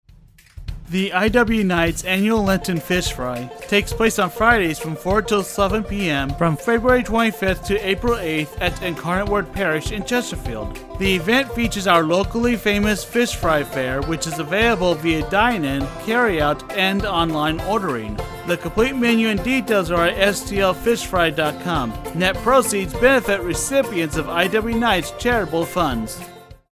Promo Spots now airing on Covenant Network radio stations in St. Louis in 2022…  *
2022 Lenten Fish Fry Radio Spots